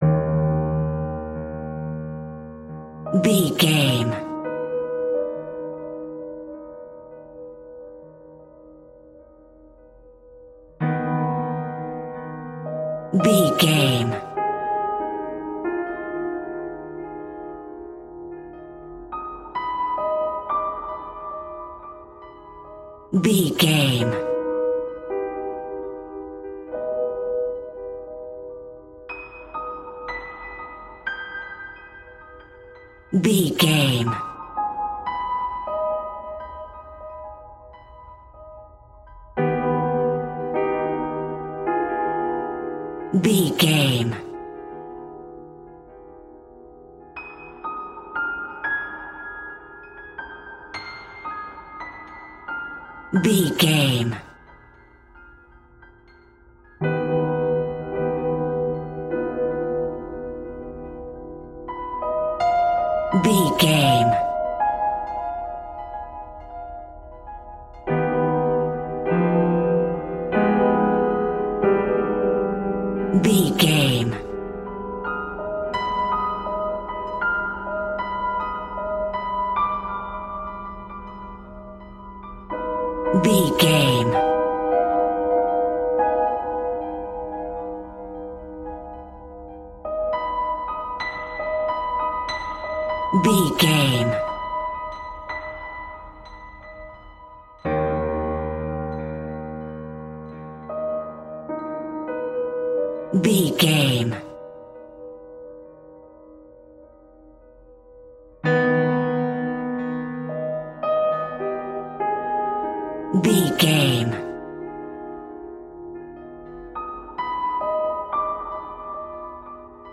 Scary Warm Piano Sounds.
Aeolian/Minor
Slow
tension
ominous
haunting
eerie